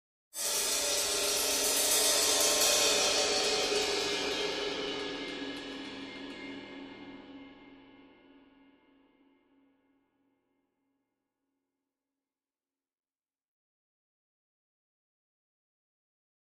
Cymbal, Thin, Crescendo, Type 2